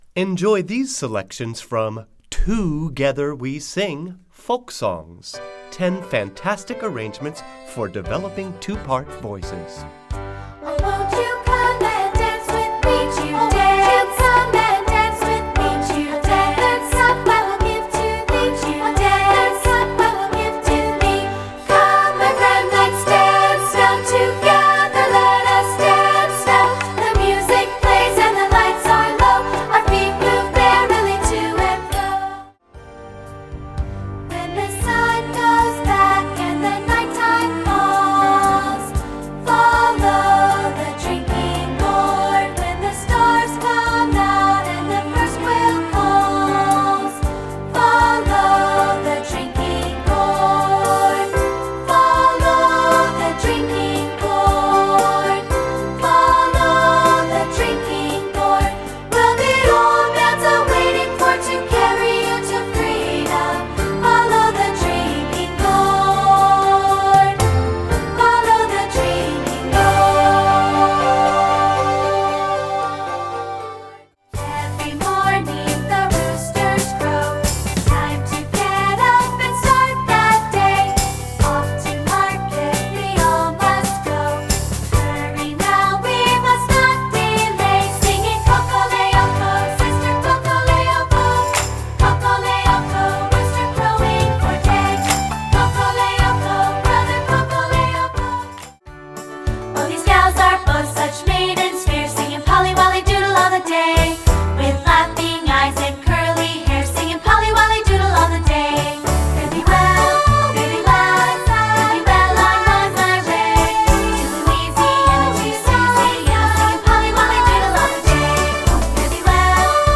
Composer: Folk Songs